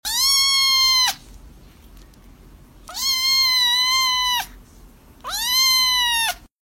Play This Sound To Call Your Cats To Your Side Immediately!